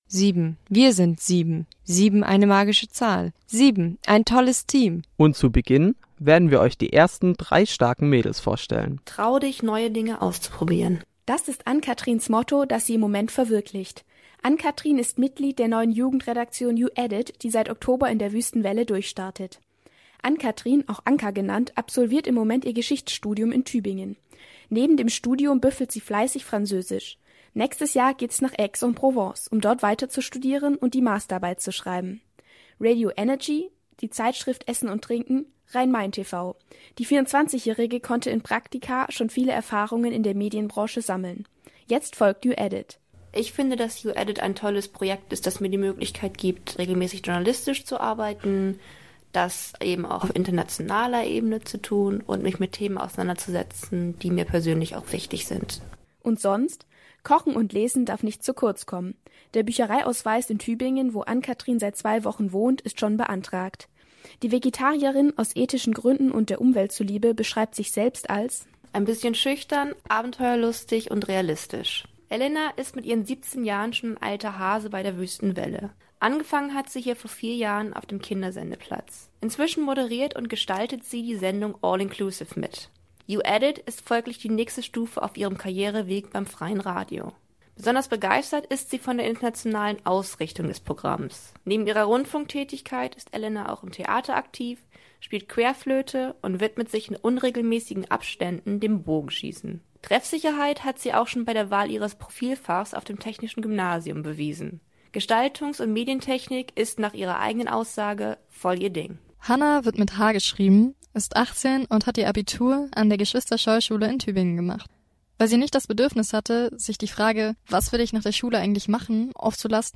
Damit Ihr mehr über uns und die Personen hinter der Stimme erfahrt, haben wir uns in diesem Interview einmal mit uns selbst befasst.